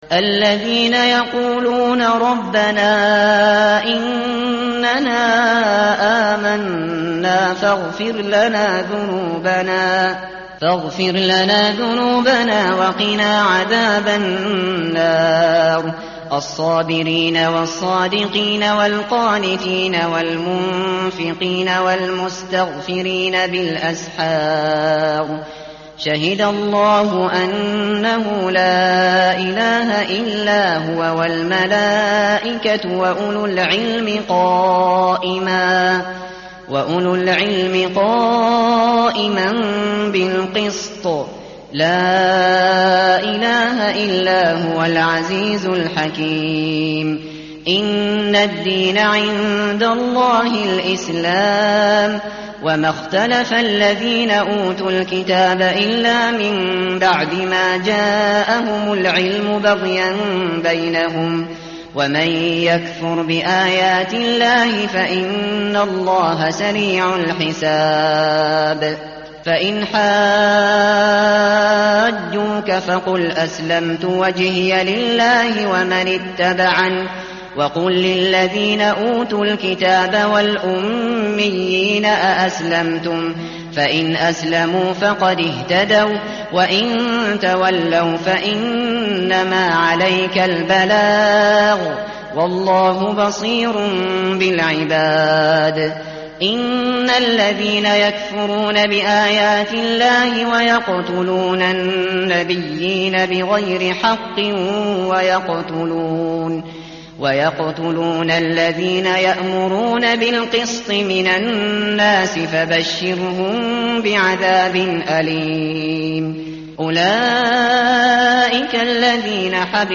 tartil_shateri_page_052.mp3